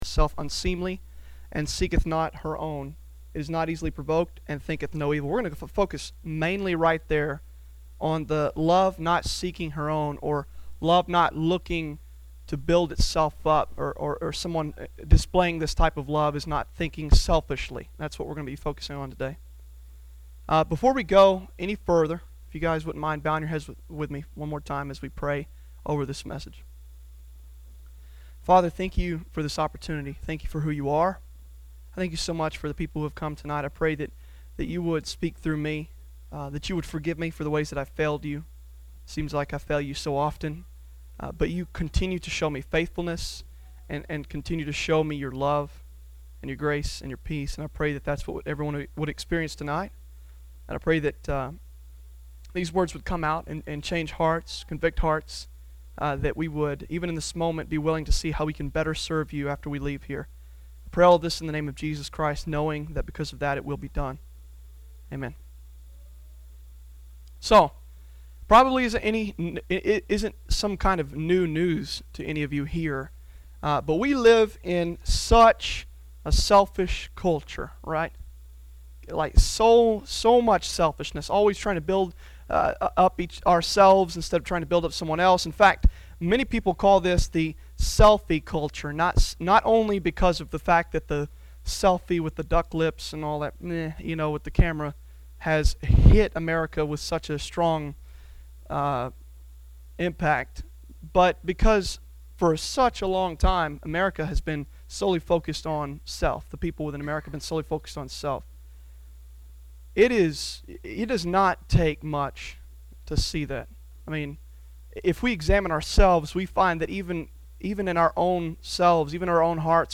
Sermons Archive - Grace MBC